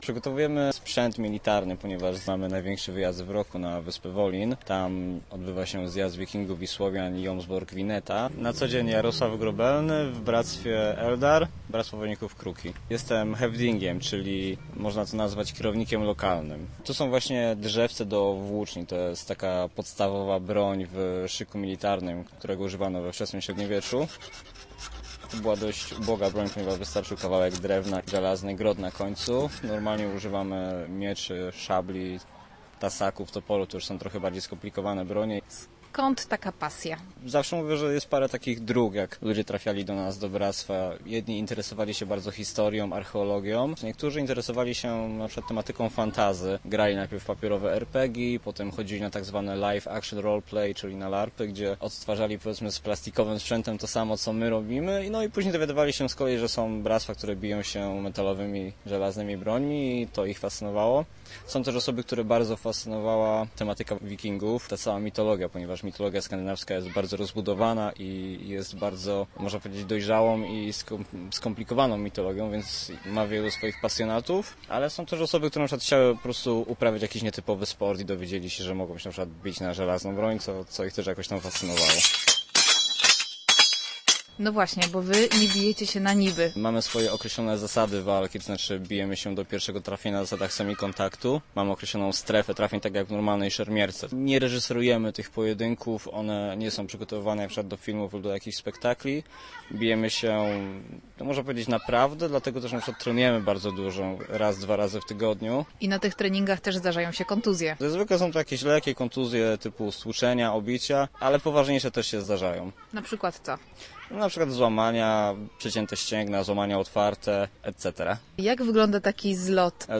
Na Wolin z serca Wielkopolski wyruszyło Bractwo Wojowników Kruki. Ostatnim przygotowaniom do wyprawy z mikrofonem przyglądała się nasza reporterka.